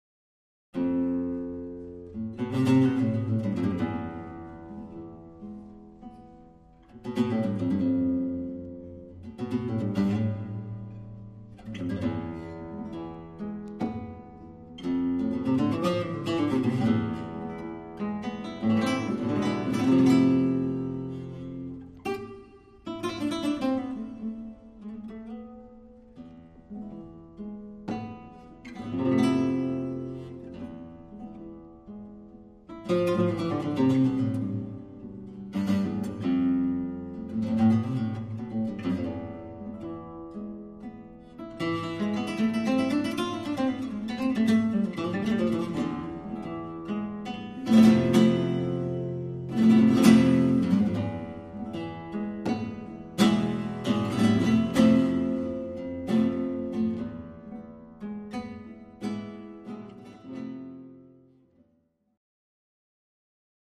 * Live Performances